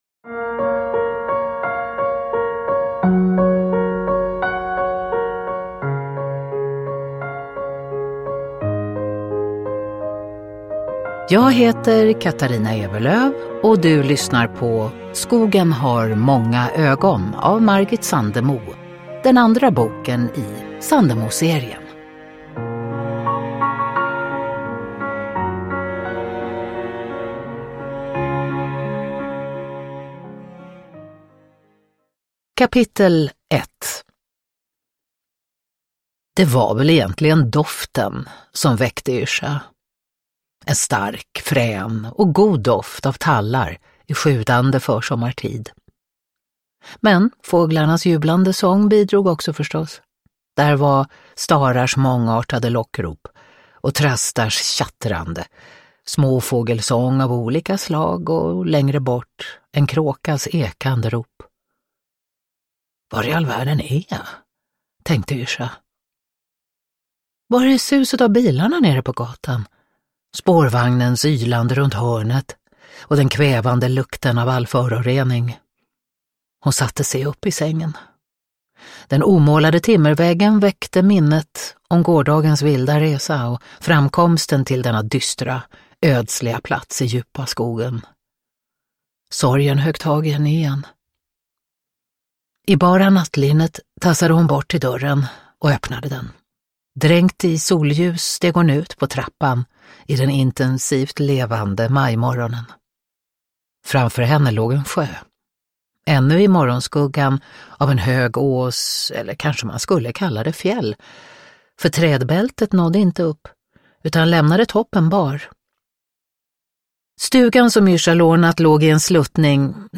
Uppläsare: Katarina Ewerlöf